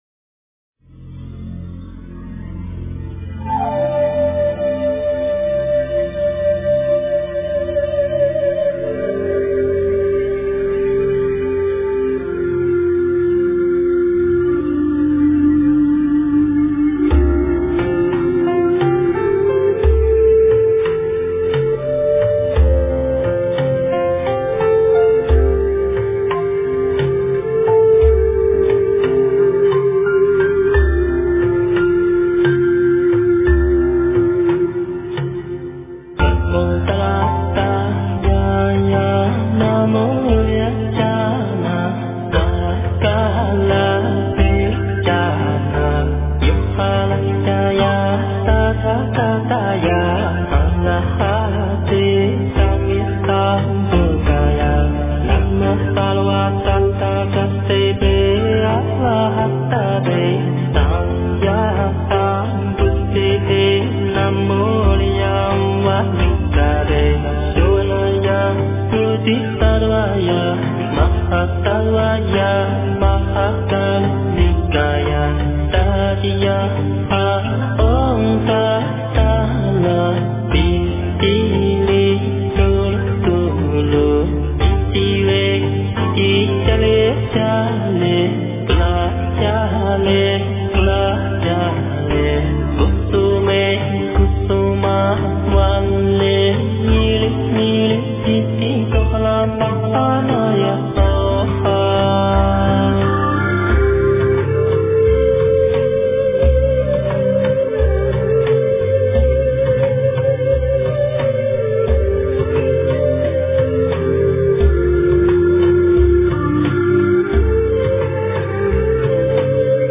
诵经
佛音 诵经 佛教音乐 返回列表 上一篇： 炉香赞 下一篇： 心经-梵唱 相关文章 今晚好好睡觉--三藏梵音 今晚好好睡觉--三藏梵音...